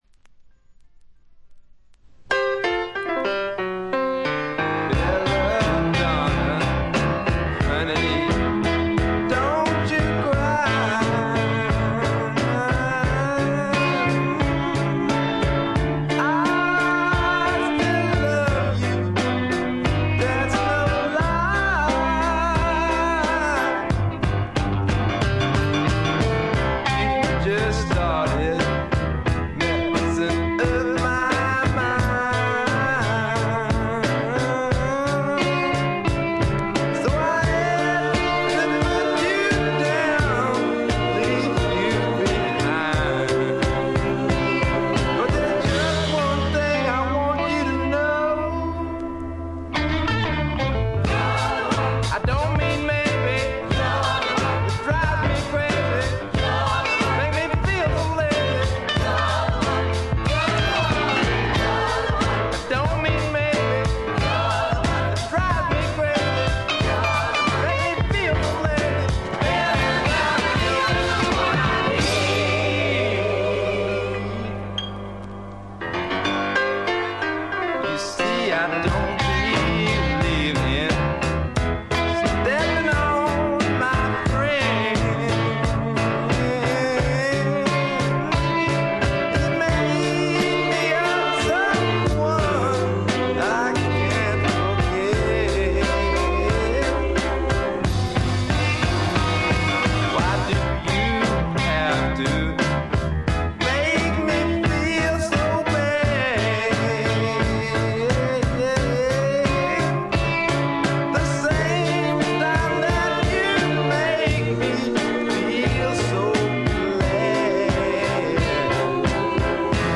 軽微なチリプチ少し。
まさしくスワンプロックの真骨頂。
試聴曲は現品からの取り込み音源です。